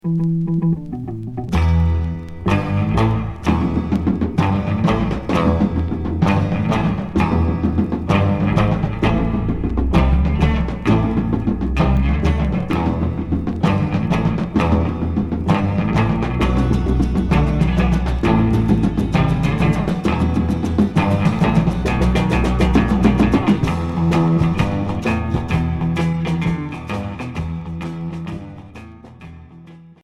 Rock et Folk